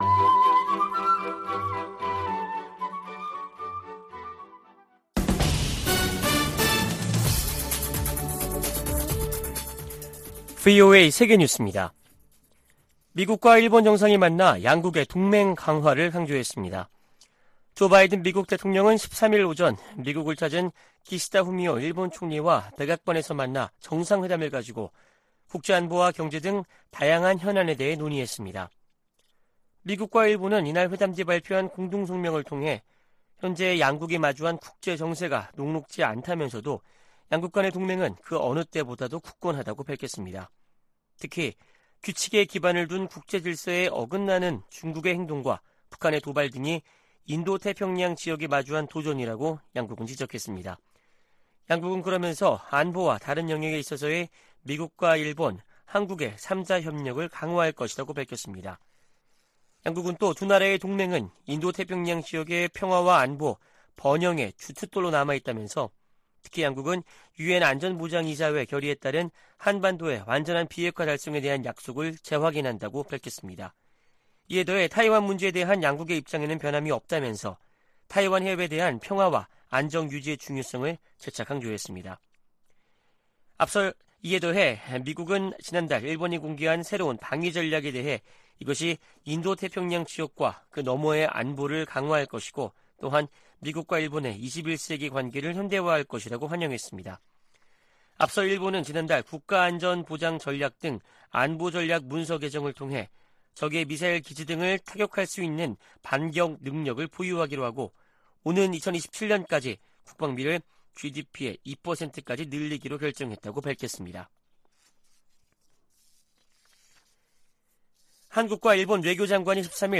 VOA 한국어 아침 뉴스 프로그램 '워싱턴 뉴스 광장' 2023년 1월 14일 방송입니다. 미 국무부는 미국과 한국, 일본이 북한의 핵과 탄도미사일 프로그램을 심각한 위협으로 받아들이고 있으며, 이를 막기 위해 3자 차원의 대응을 강화하고 있다고 밝혔습니다. 미국 백악관은 윤석열 한국 대통령의 자체 핵보유 언급과 관련해 한반도의 완전한 비핵화 입장에 변함이 없다고 강조했습니다.